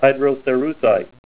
Help on Name Pronunciation: Name Pronunciation: Hydrocerussite + Pronunciation